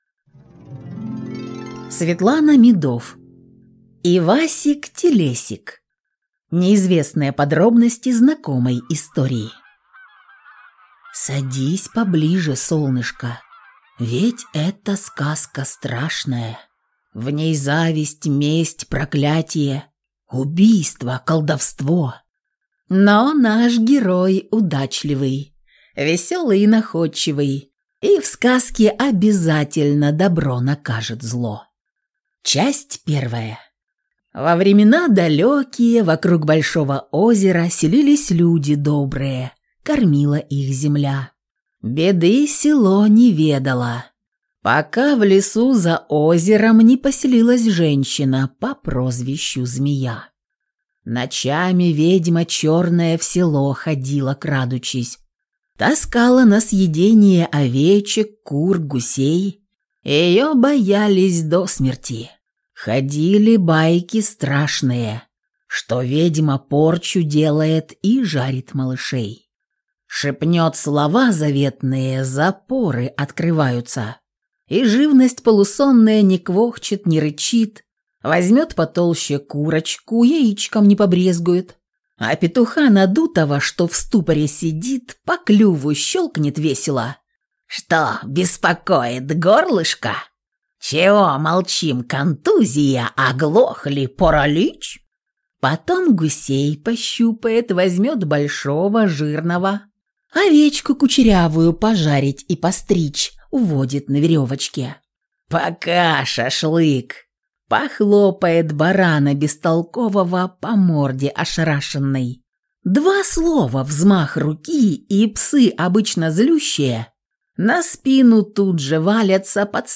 Аудиокнига Ивасик-Телесик. Неизвестные подробности знакомой истории | Библиотека аудиокниг